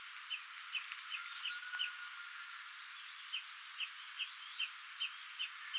tuollainen kuovin ääntely
tuollainen_kuovin_aantely.mp3